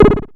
Vermona Perc 01.wav